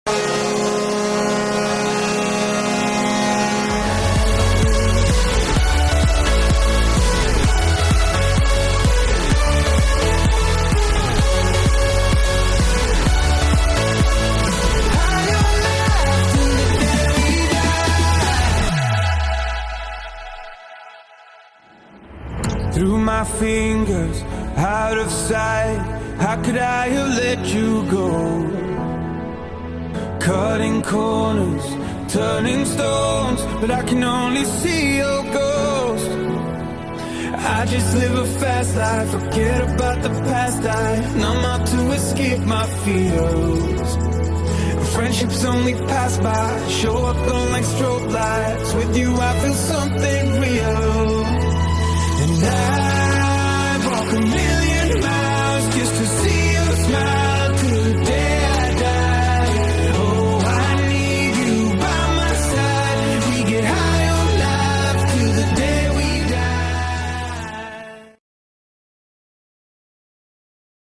File:Floria Junior goal horn.mp3